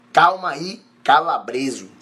Frase de Davi Brito do Big Brother Brasil 24